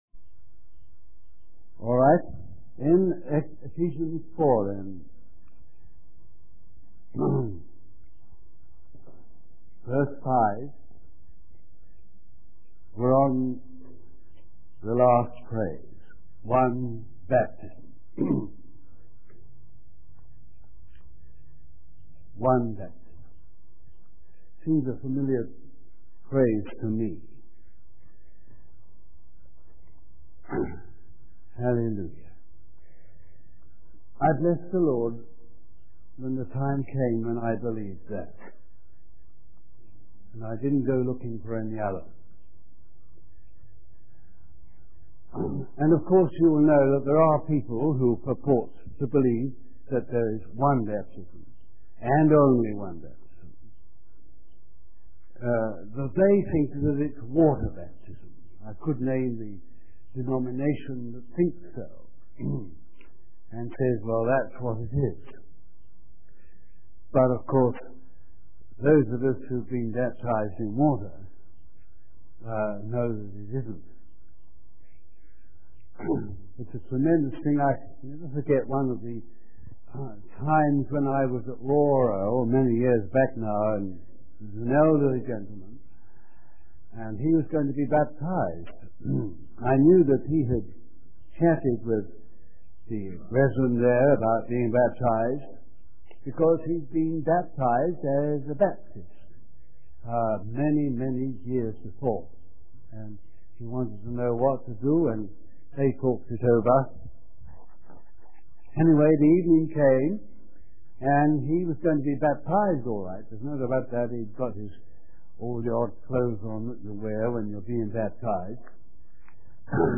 The preacher also highlights the significance of believing in Jesus Christ for the forgiveness of sins, healing of the body, and receiving the Holy Spirit. He encourages the congregation to never let people rest in sin and to cast out the devil.